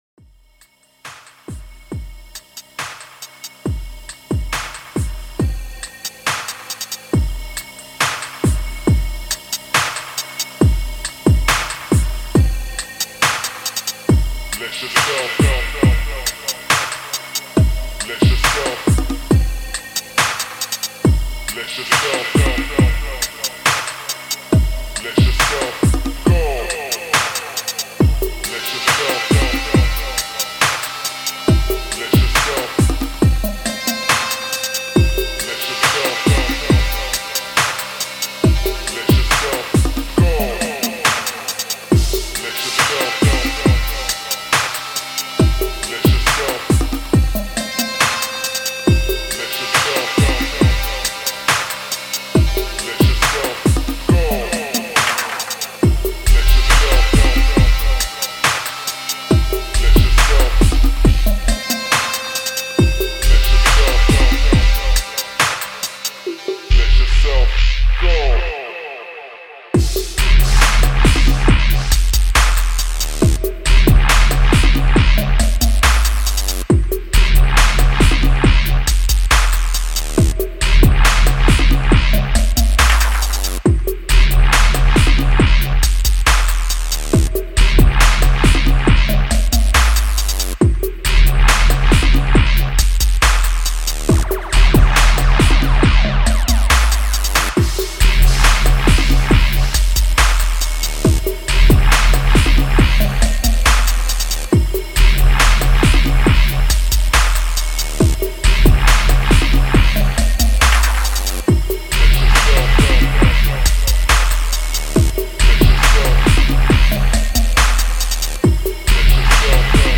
ez dubstep forum,